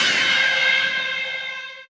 DM PAD6-2.wav